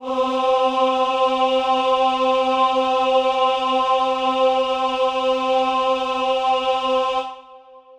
Choir Piano
C4.wav